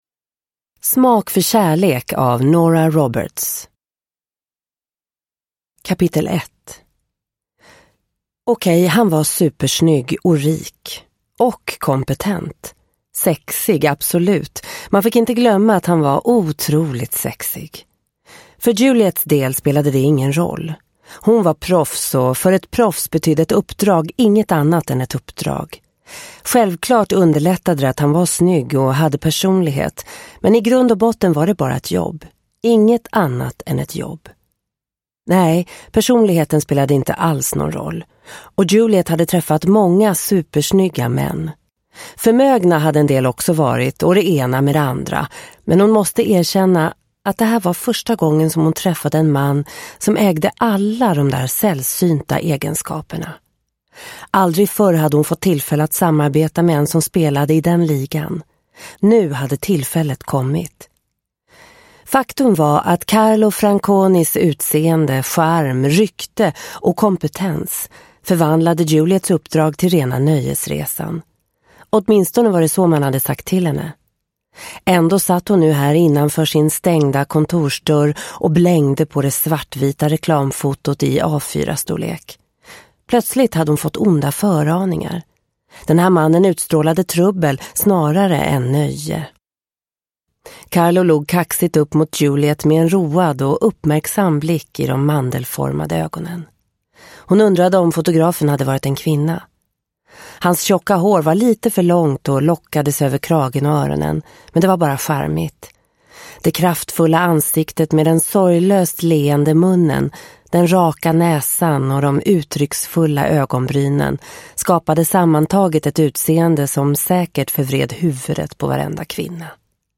Smak för kärlek – Ljudbok – Laddas ner